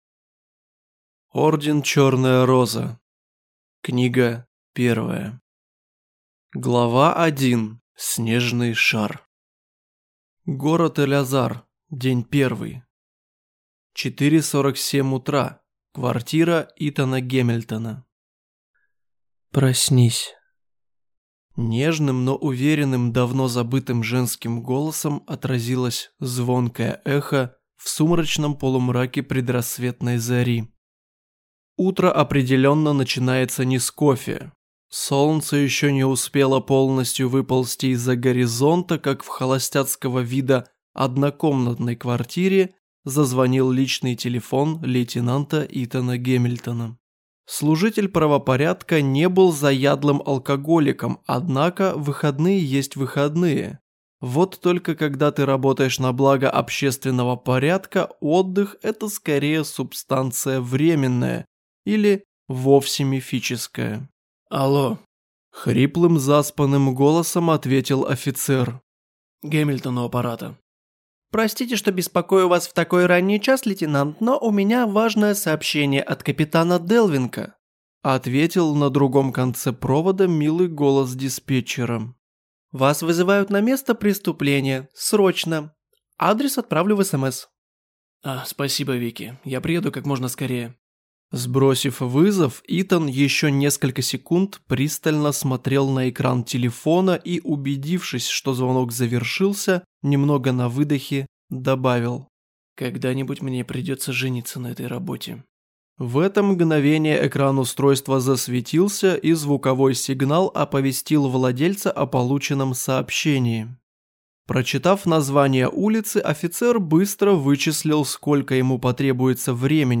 Аудиокнига Орден «Чёрная Роза» | Библиотека аудиокниг
Прослушать и бесплатно скачать фрагмент аудиокниги